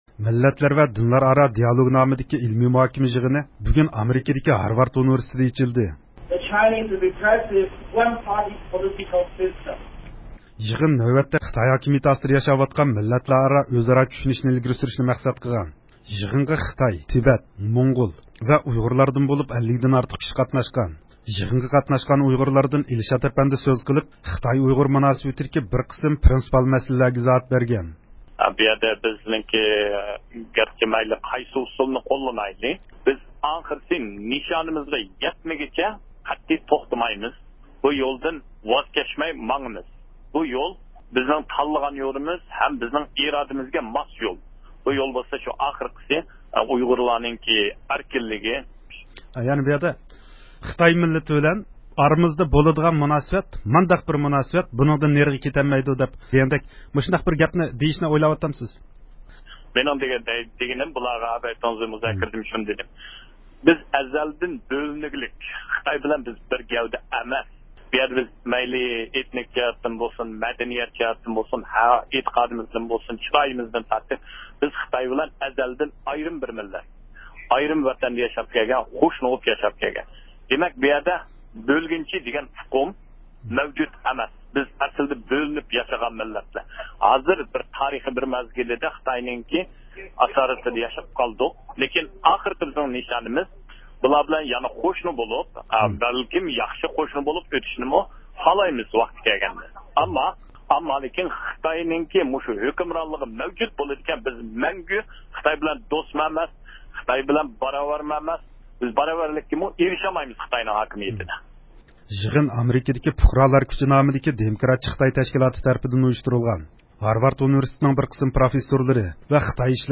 مۇخبىرىمىز